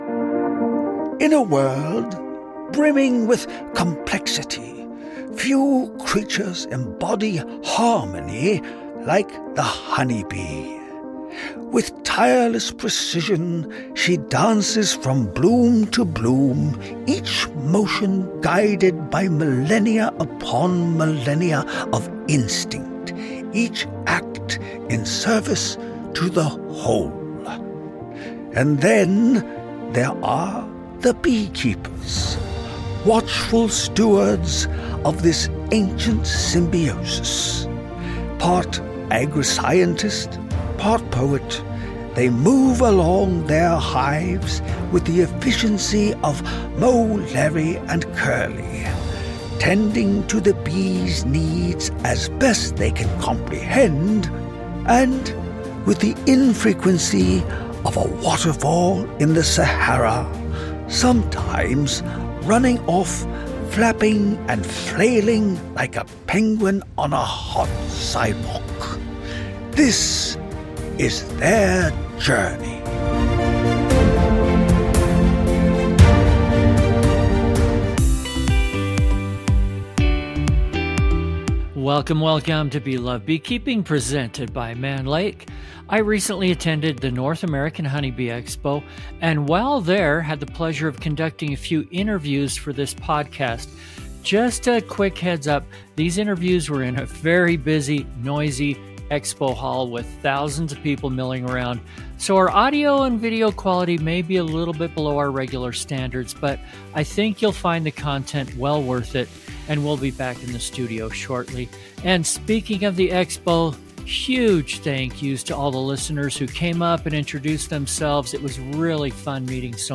Recorded live on the bustling expo floor, this episode blends science, storytelling, and practical beekeeping insight.